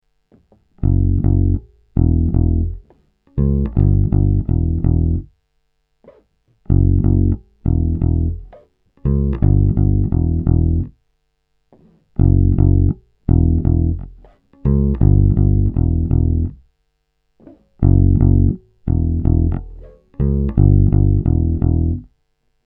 Bassriff-Quiz